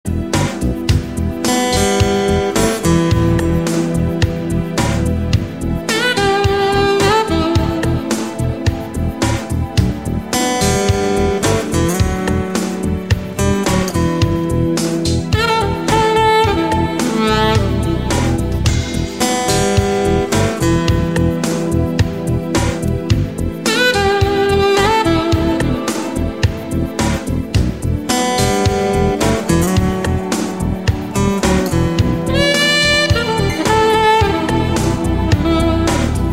• Качество: 128, Stereo
гитара
спокойные
без слов
инструментальные
ретро
Спокойная инструментальная композиция